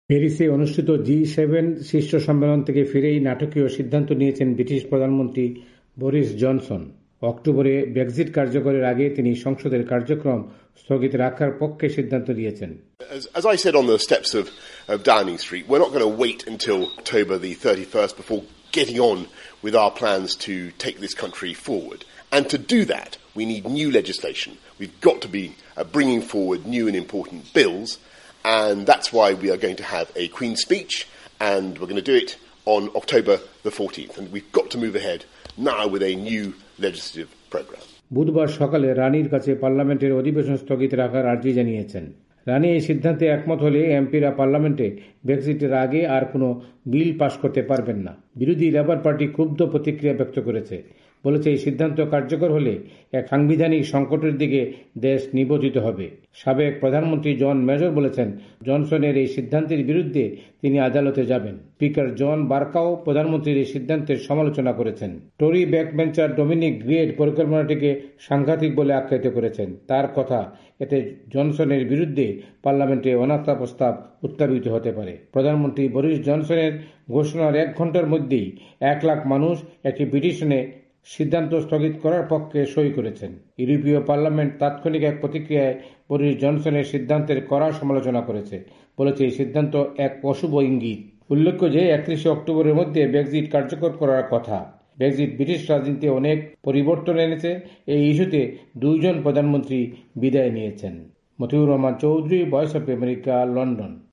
এ সম্পর্কে বিস্তারিত জানিয়েছেন লন্ডন থেকে